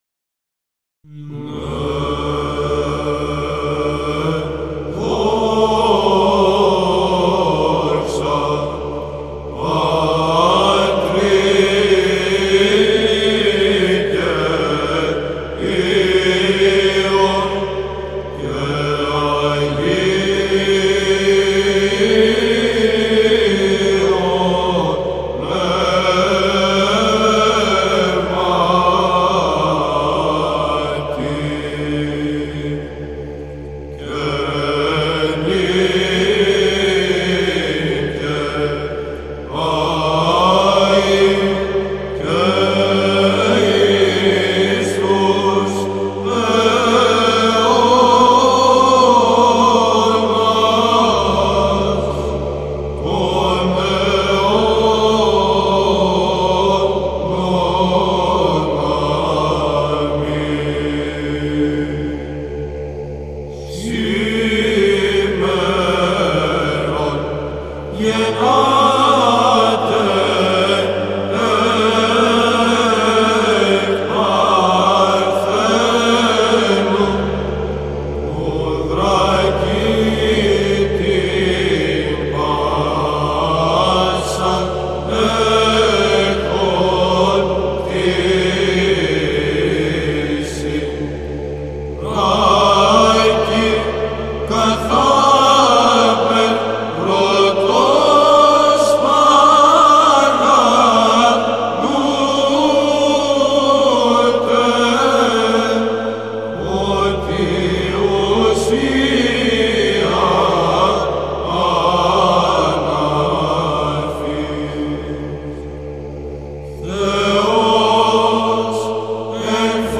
ΒΥΖΑΝΤΙΝΟΙ ΥΜΝΟΙ ΧΡΙΣΤΟΥΓΕΝΝΩΝ
δοξαστικόν ιδιόμελον, ήχος πλ.β΄